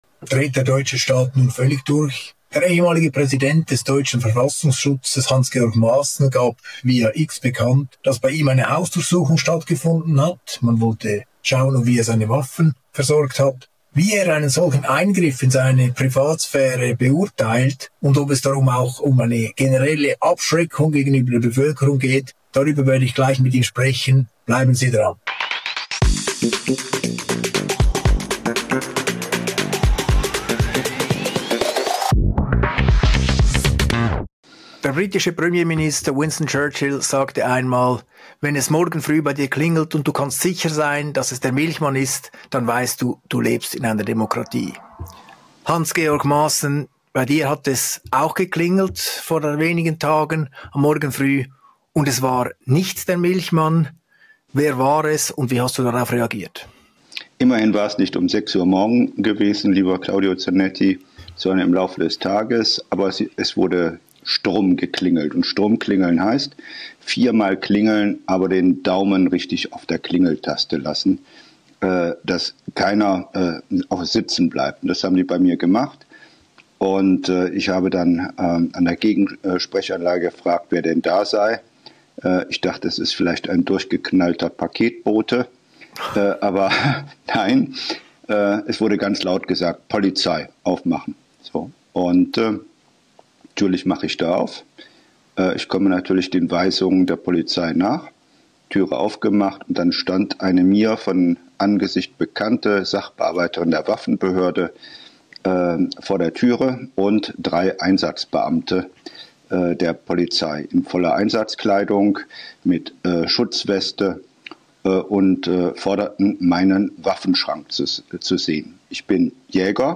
Beschreibung vor 4 Monaten In einem Interview schildert Hans-Georg Maaßen eine unangekündigte Kontrolle seiner Waffen durch die Behörden.